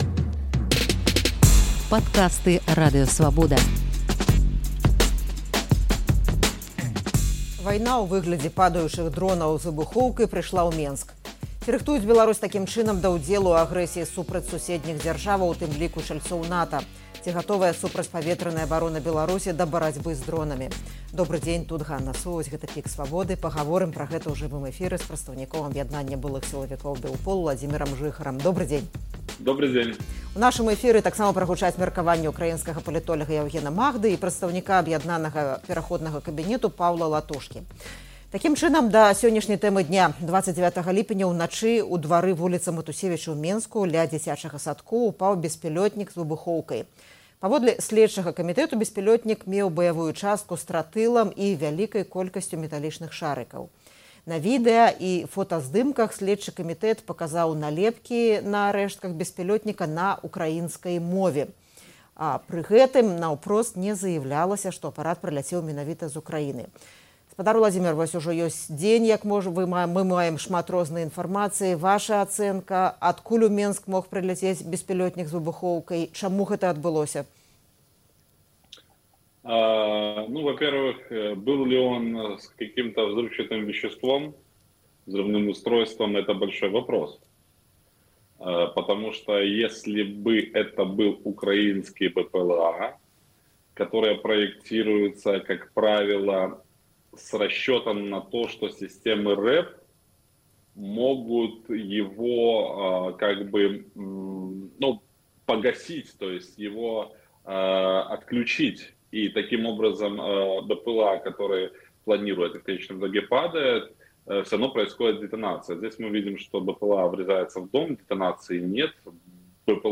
Ці гатовая супрацьпаветраная абарона Беларусі да барацьбы з дронамі? У эфіры «ПіКа Свабоды» гаворым пра гэта